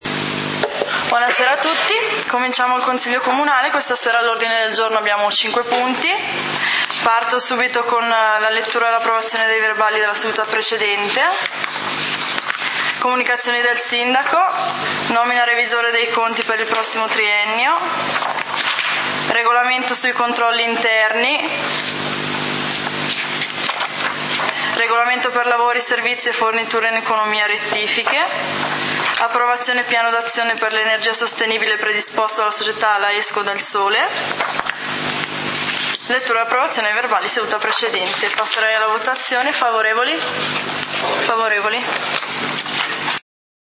Punti del consiglio comunale di Valdidentro del 30 Aprile 2013